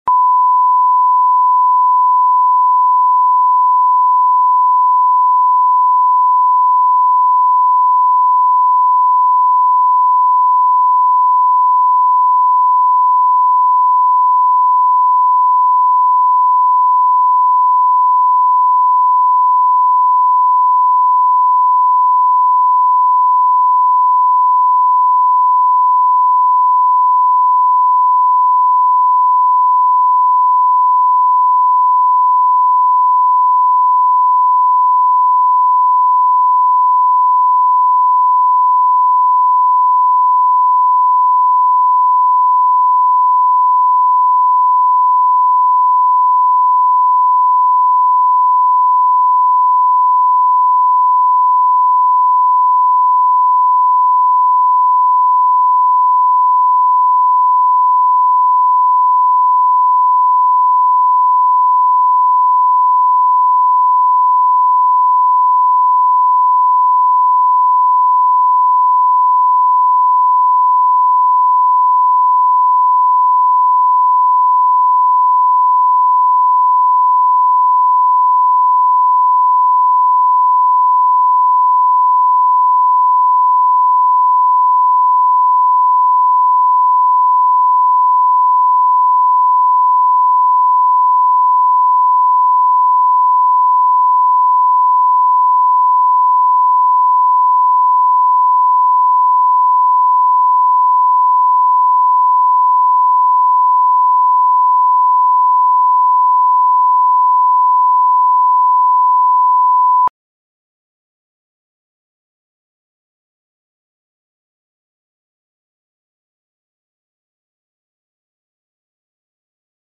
Аудиокнига Мундир и скрипка | Библиотека аудиокниг
Прослушать и бесплатно скачать фрагмент аудиокниги